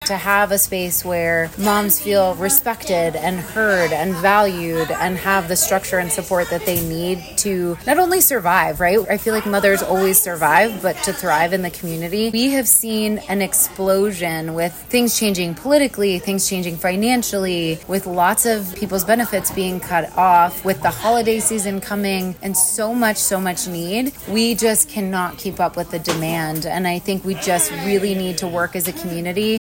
had its official grand opening last night, which included remarks from Roanoke Mayor Joe Cobb. The Huddle Up Moms Support Shop and Diaper Bank in downtown Roanoke on Marshall Avenue offers diapers, baby wipes and other supplies for new and expectant mothers that are struggling financially.